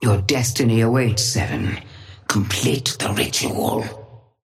Sapphire Flame voice line - Your destiny awaits, Seven. Complete the ritual.
Patron_female_ally_gigawatt_start_05.mp3